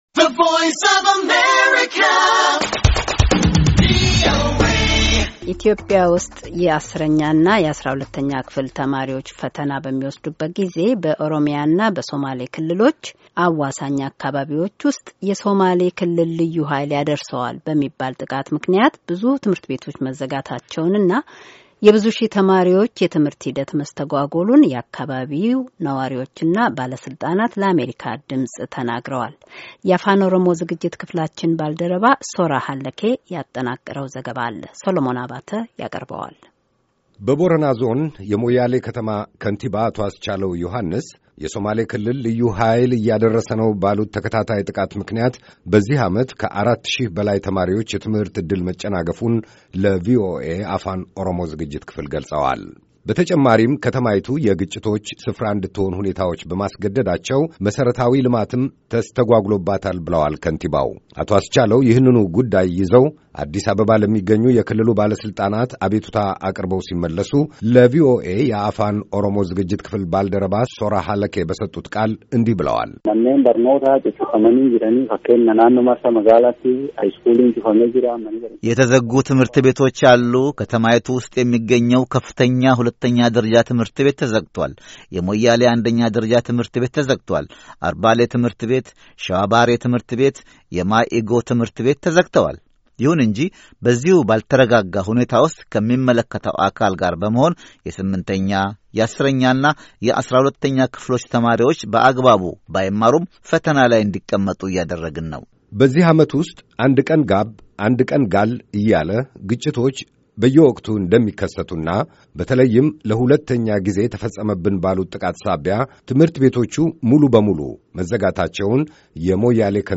ኢትዮጵያ ውስጥ የአሥረኛና የአሥራ ሁለተኛ ክፍሎች ተማሪዎች ፈተና በሚወስዱበት ጊዜ በኦሮምያና በሶማሌ ክልሎች አዋሣኝ አካባቢዎች ውስጥ “የሶማሌ ክልል ልዩ ኃይል ያደርሰዋል” በሚባል ጥቃት ምክንያት ብዙ ትምህርት ቤቶች መዘጋታቸውንና የብዙ ሺህ ተማሪዎች የትምህርት ሂደት መስተጓጎሉን የአካባቢው ነዋሪዎችና ባለሥልጣናት ለአሜሪካ ድምፅ ተናግረዋል።